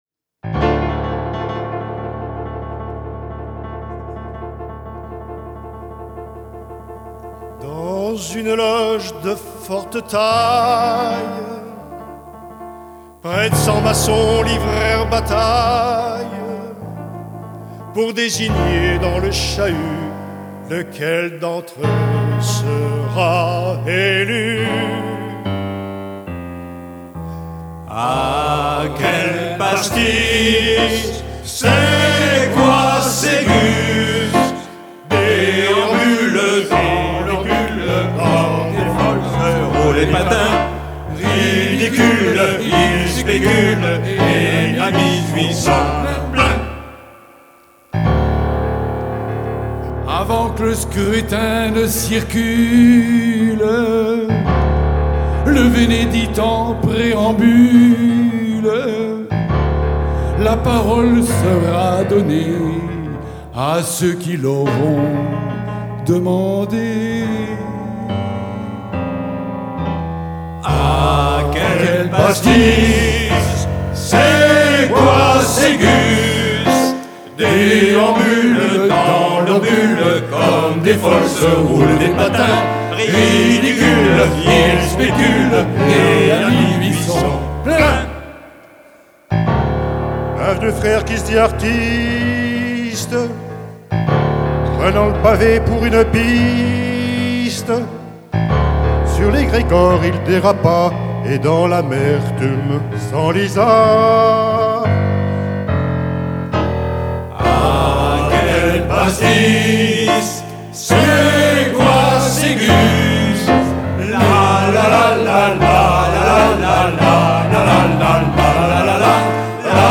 Chansons maçonneuses                                       suivante
Enregistrement public festival d’Humour 2013